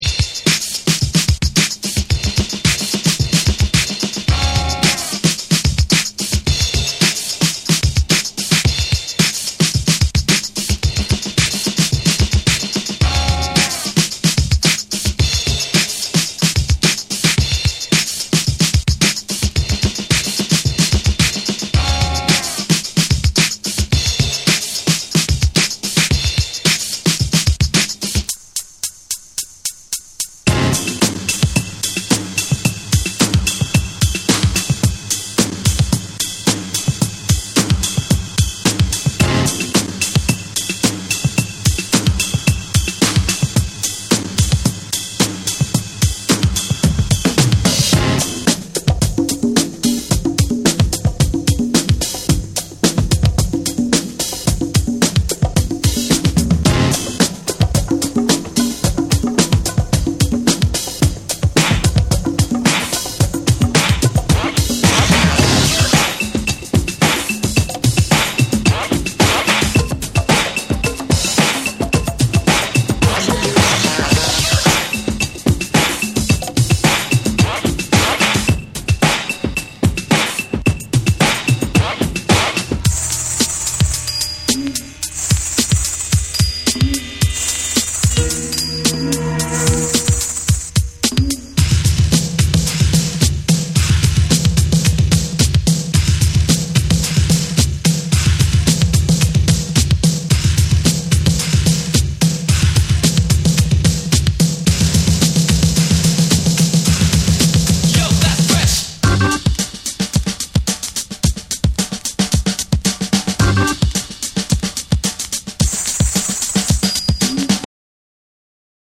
多彩な大ネタが次々と飛び出し、強烈な勢いで突き進むハイクオリティ・ブレイクビーツ。
BREAKBEATS